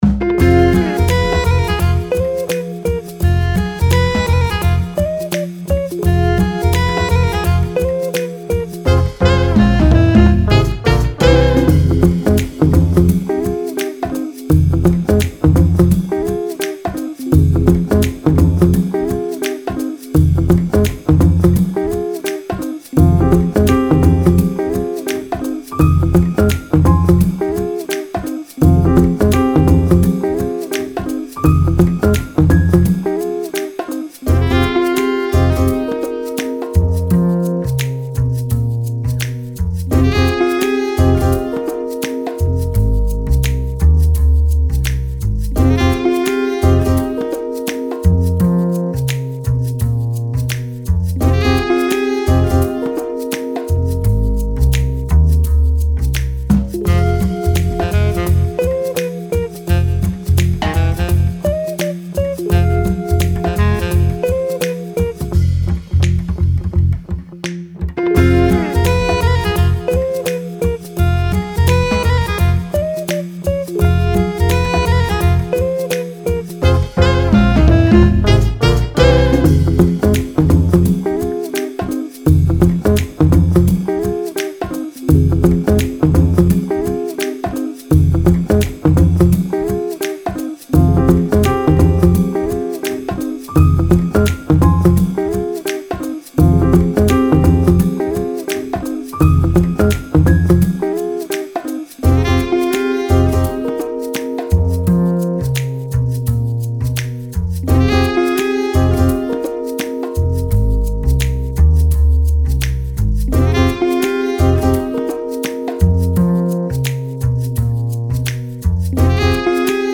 謎解き・ミステリー・調査に合うBGM
アコースティック, ジャズ 3:24